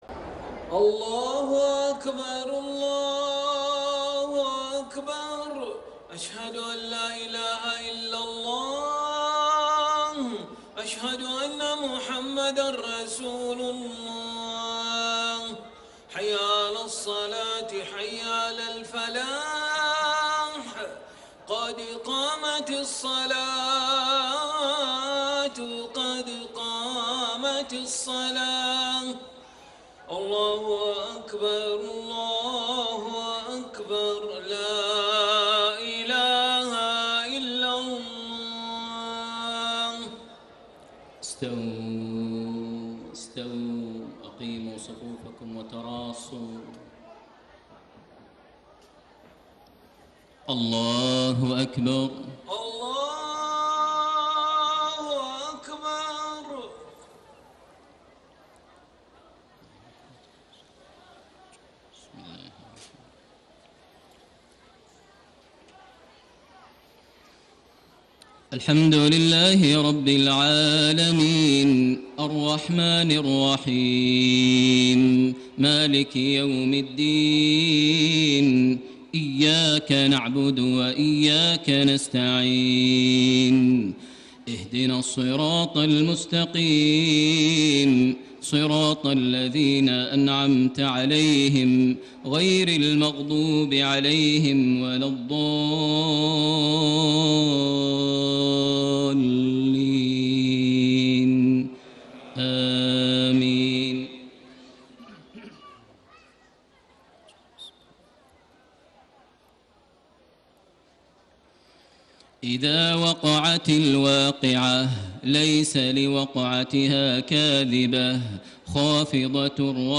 صلاة العشاء 2-2-1435 من سورة الواقعة > 1435 🕋 > الفروض - تلاوات الحرمين